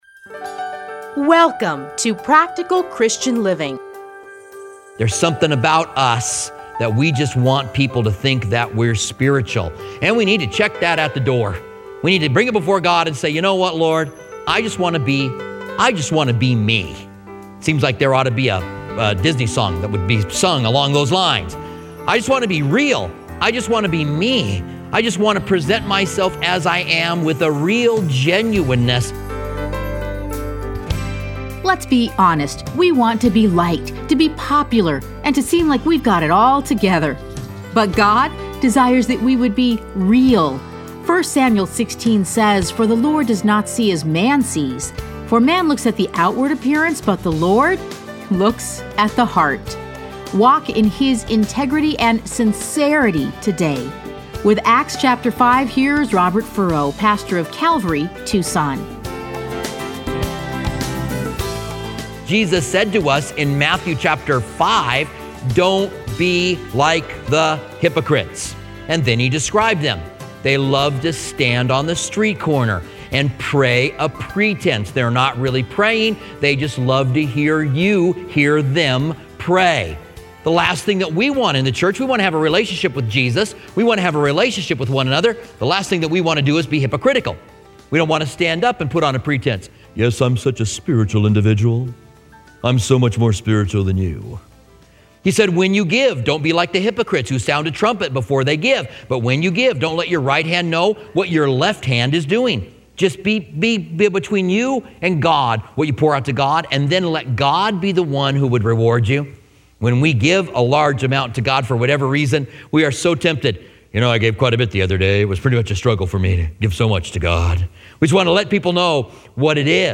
Listen to a teaching from Acts 5.